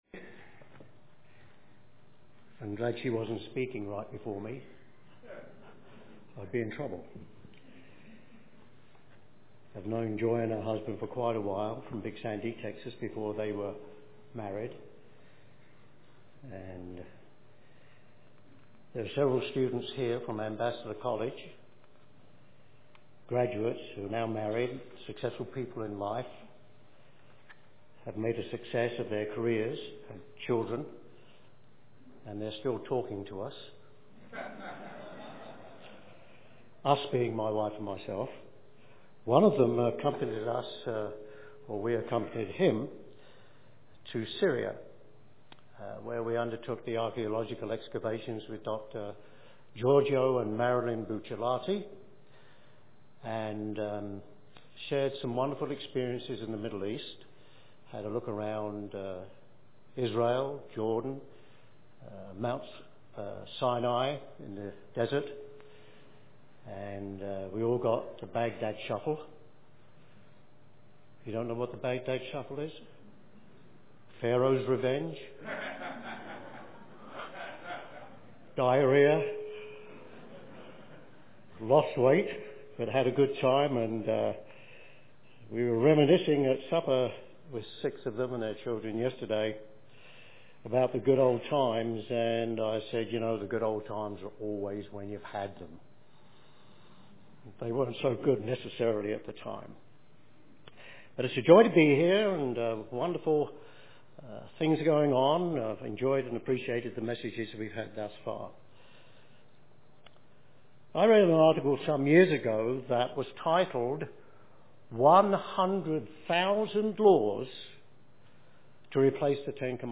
This sermon was given at the Steamboat Springs, Colorado 2013 Feast site.